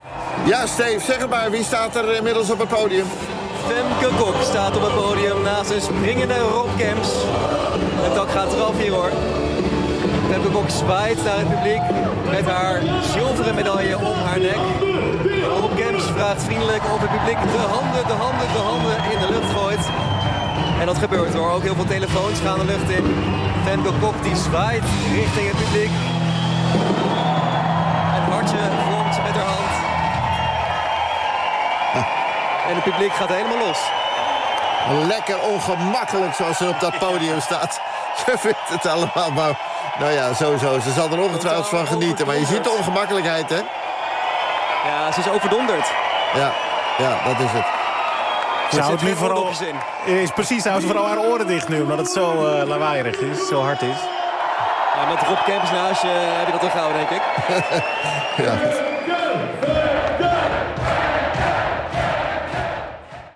Vanuit een kolkend Team NL Huis in Milaan de huldiging van schaatster Femke Kok, die zilver pakte op de 1.000 meter. Een live-verslag in het programma Langs de Lijn En Omstreken: